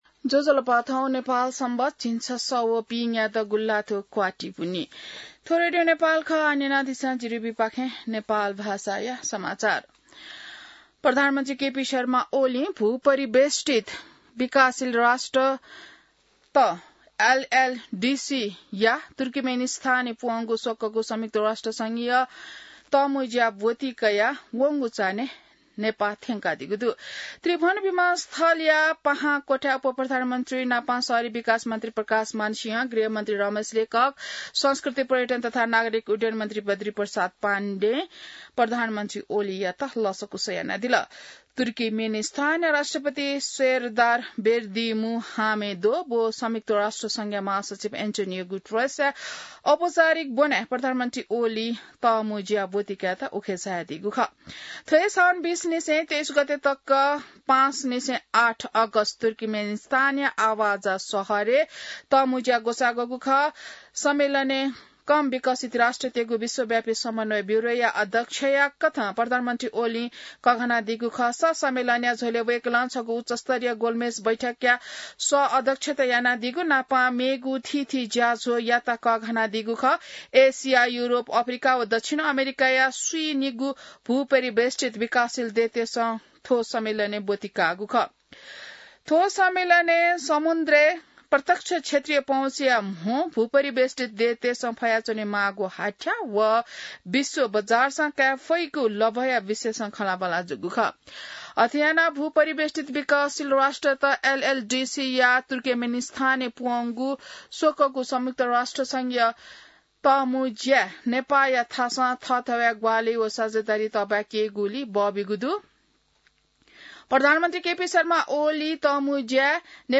नेपाल भाषामा समाचार : २४ साउन , २०८२